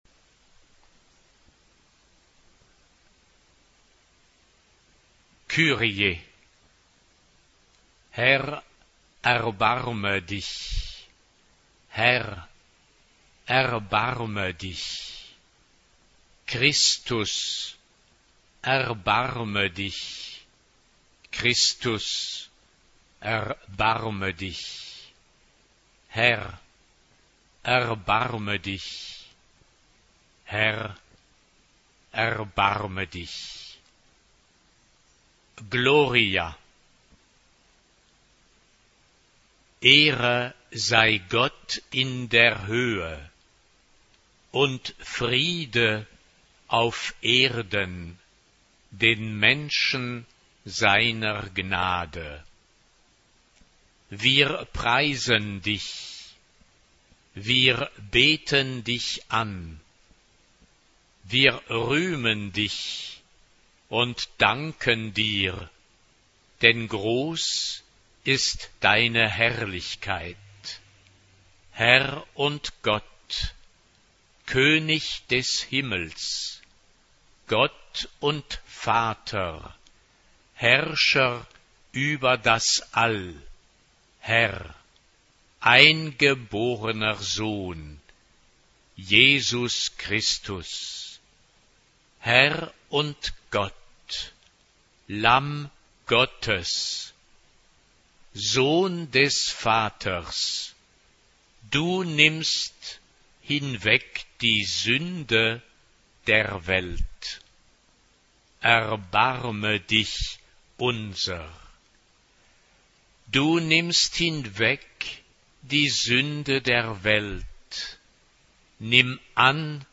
SATB (4 gemischter Chor Stimmen) ; Partitur. Verlag: Carus-Verlag Stuttgart. geistlich. romantisch.
Charakter des Stückes: mäßig
Tonart(en): a-moll